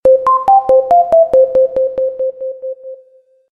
Категория: SMS рингтоны | Теги: SMS рингтоны